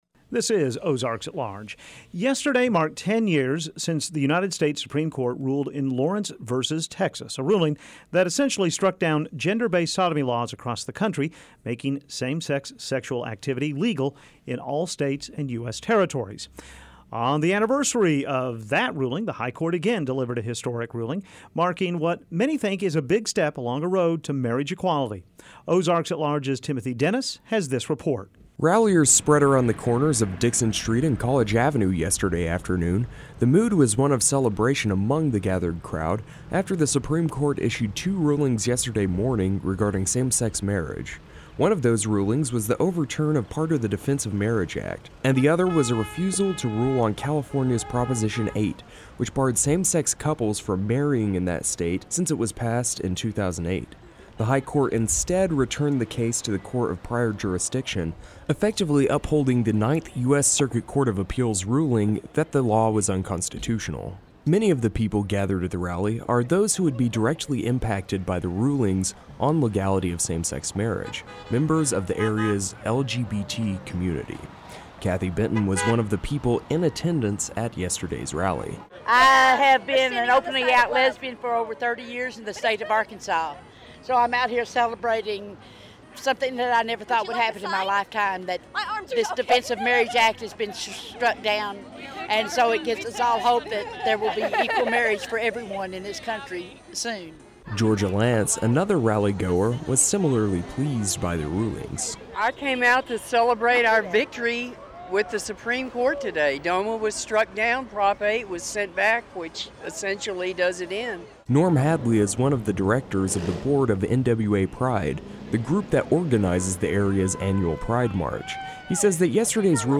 A rally was held yesterday afternoon by the NWA Center for Equality, hours after the U.S. Supreme Court issued two rulings regarding same-sex marriage. We go on the scene to get a pulse on how members of the local LGBT community feel about the rulings, and we speak with a legal expert with the ACLU of Arkansas to find out what the rulings mean for same-sex couples in the Natural State.